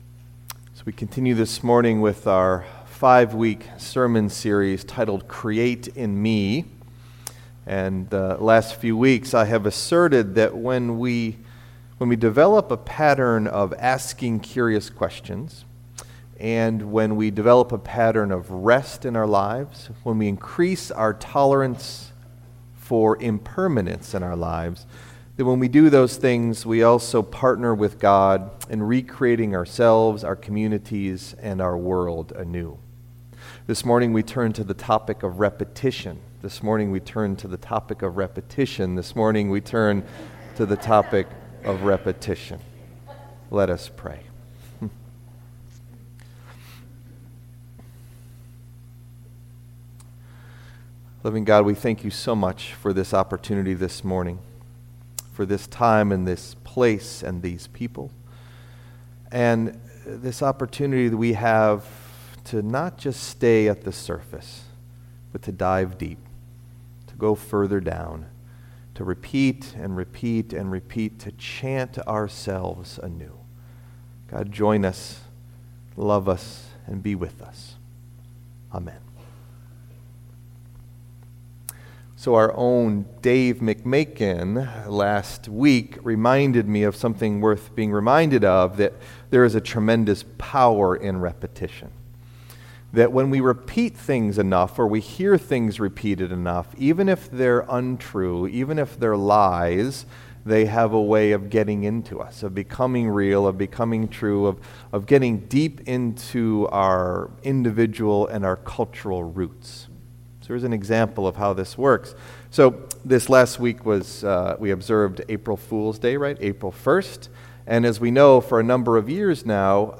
Our topic today is REPETITION. This is a shorter meditation, preached as part of a contemplative service of prayer and chants.
Message Delivered at: Charlotte Congregational Church (UCC)